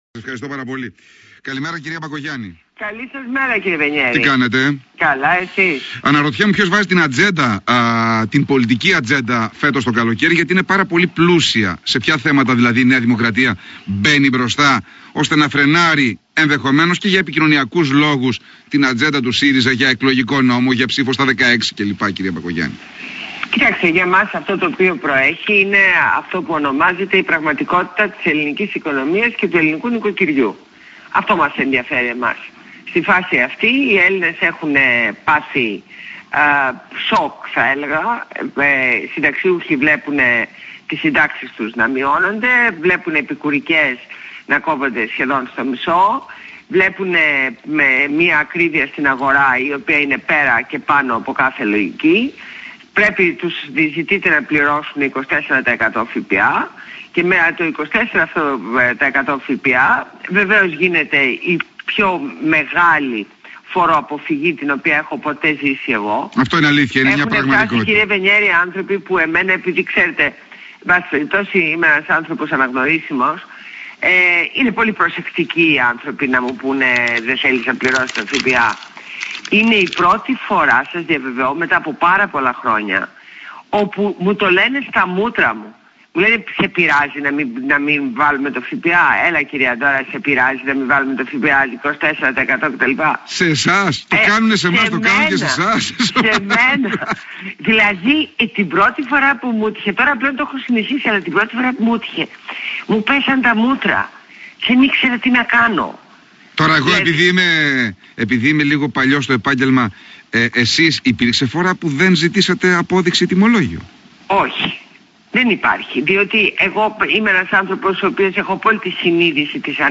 Συνέντευξη στο ραδιόφωνο FOCUS fm